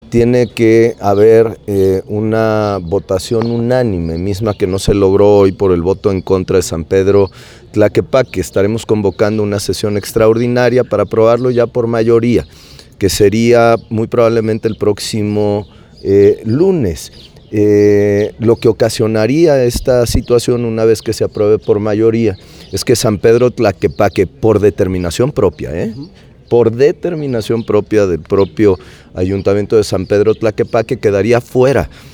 Habla el gobernador Pablo Lemus